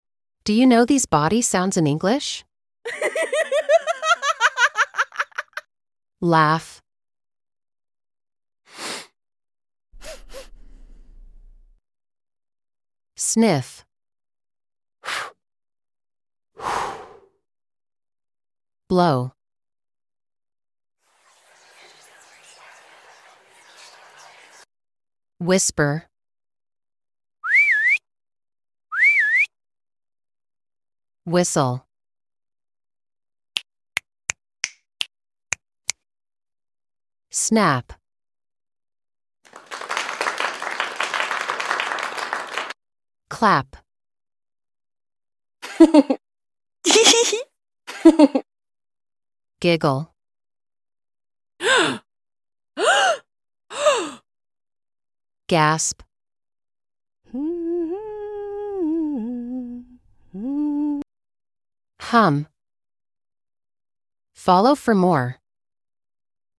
English vocabulary: Do you know these human body sounds in English? How many did you get?